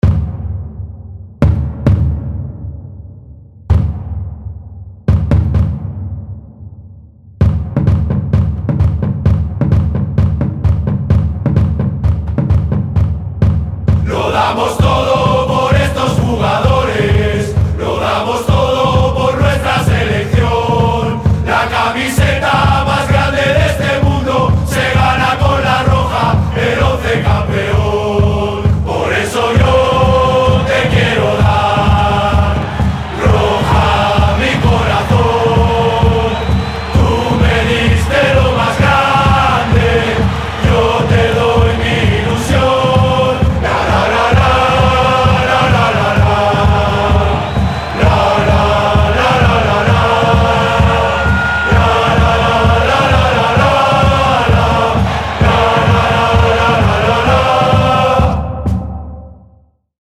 un canto de ánimo y apoyo a La Roja